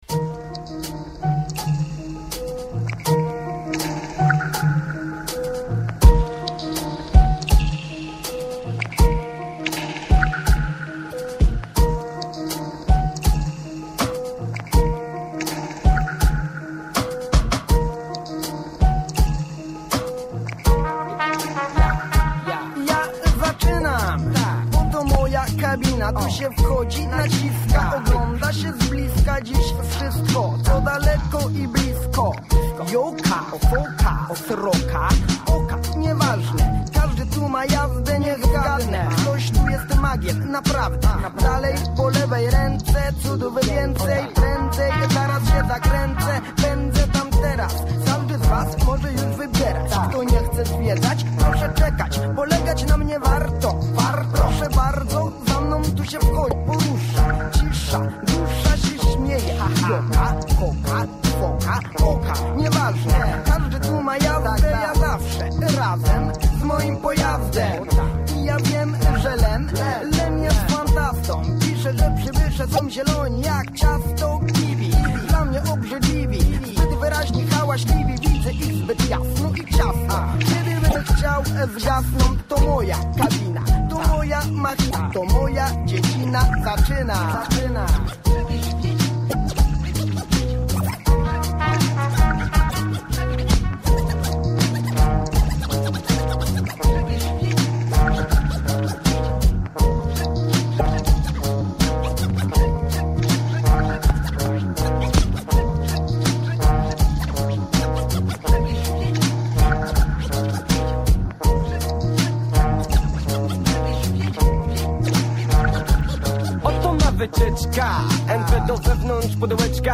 W tym odcinku usłyszycie raperów, o których upominali się słuchacze odkąd wystartował cykl Rap na fali.